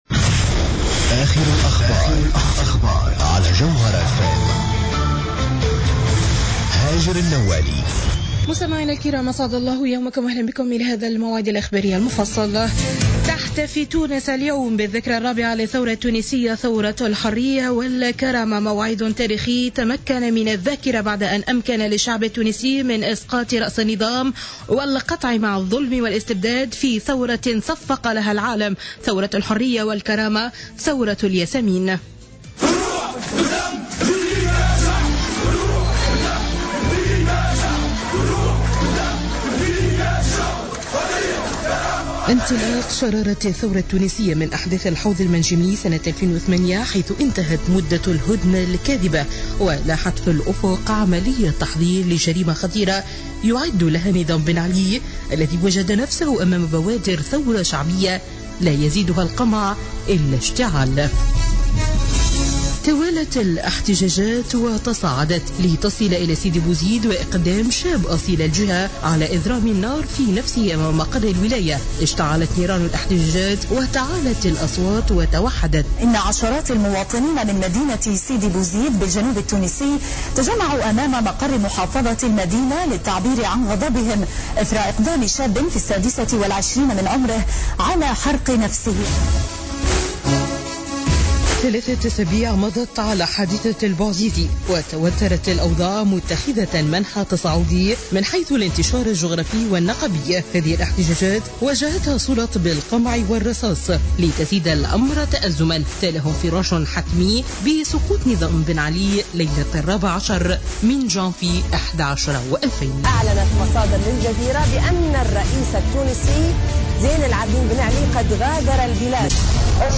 نشرة أخبار منتصف الليل ليوم الإربعاء 14 جانفي 2014